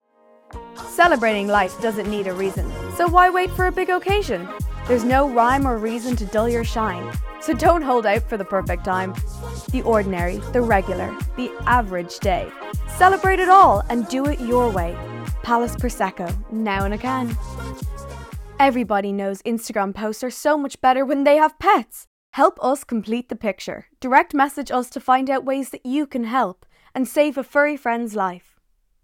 Female
Teens
Irish Dublin Neutral, Irish Neutral